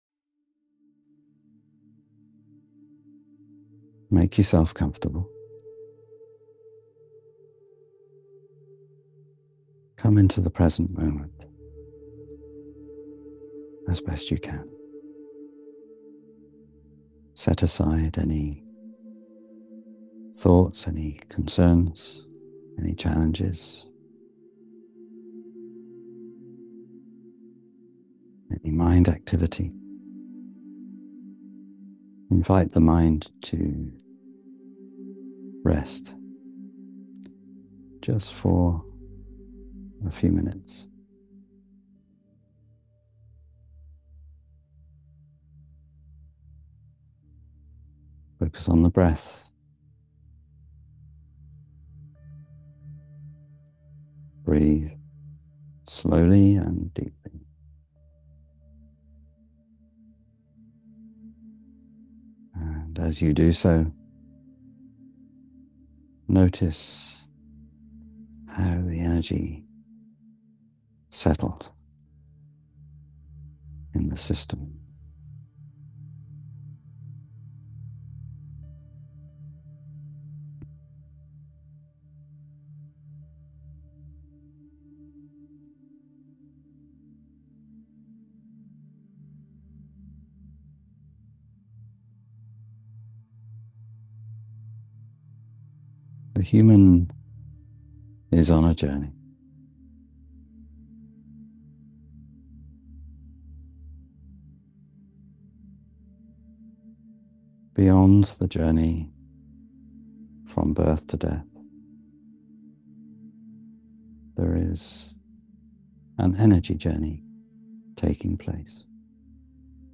Another channelled meditation from the unseen. This 20-minute meditation invites you to connect with the oneness that exists beyond the human experience.